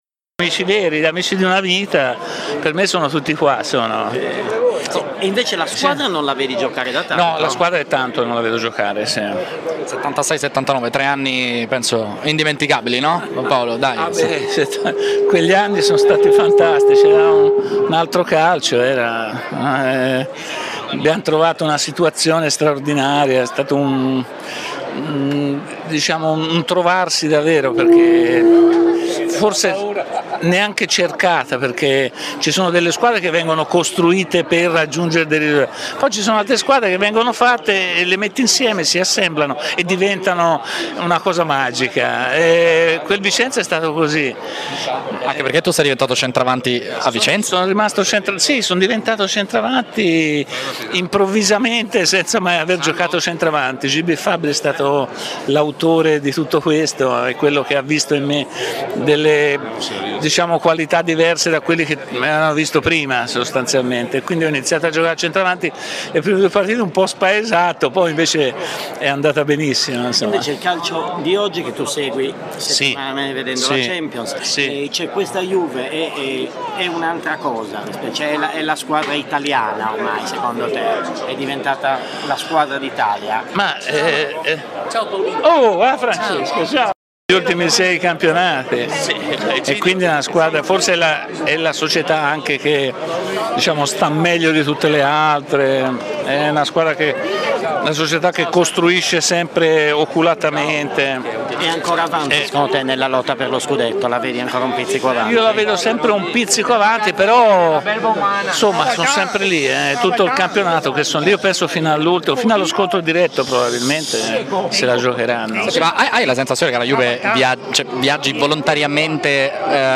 Paolo Rossi, ex giocatore, intervistato
alla festa dei 116 anni del Vicenza